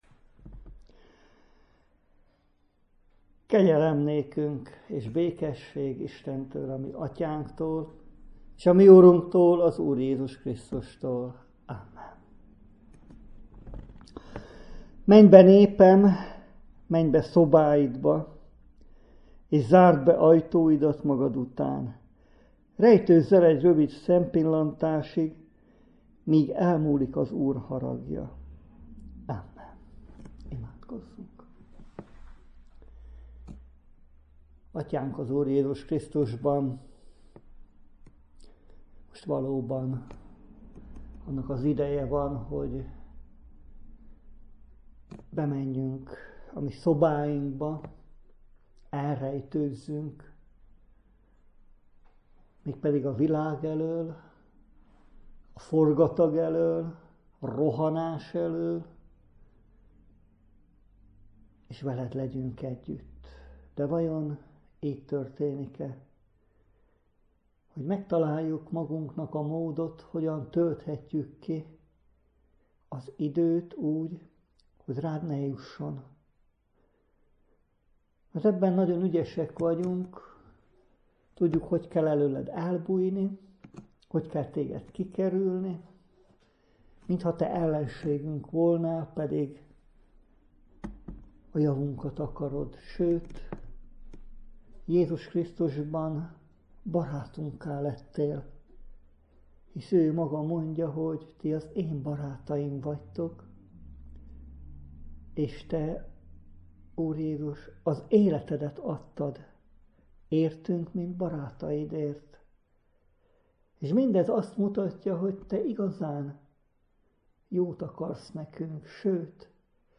2020. április 16. igehirdetés